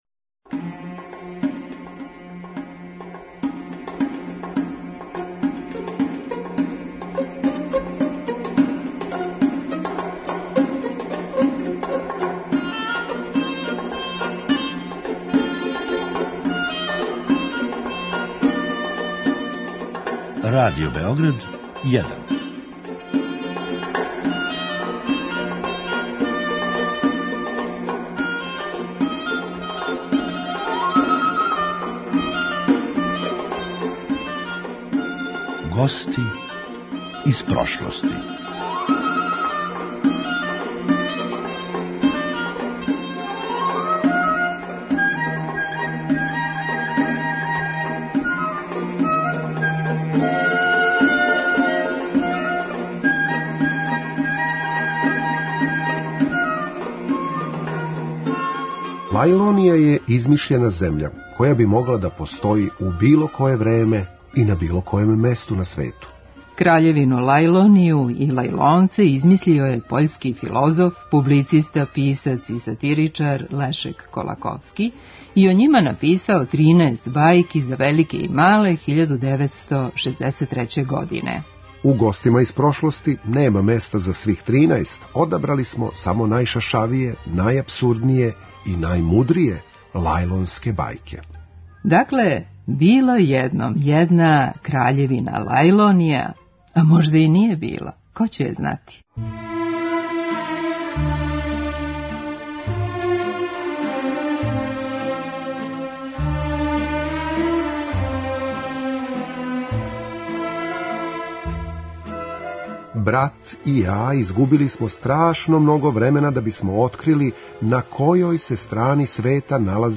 У „Гостима из прошлости" нема места за свих тринаест, одабрали смо само најшашавије, најапсурдније и најмудрије лајлонске бајке и прилагодили их за радијско слушање.